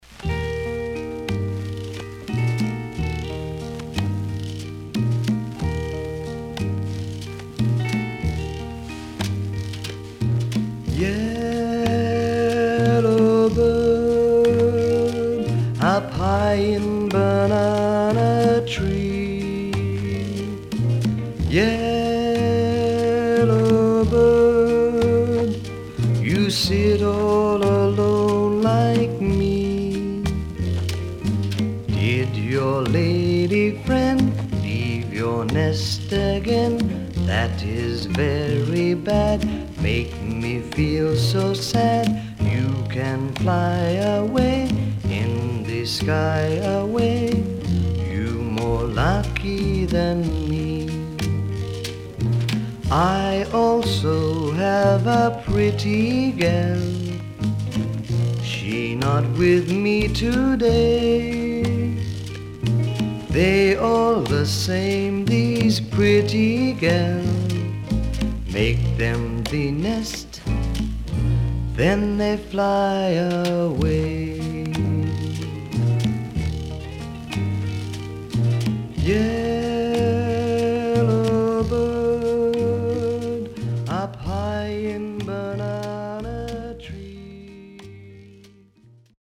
SIDE A:少しノイズあり、曲によってヒスが入りますが良好です。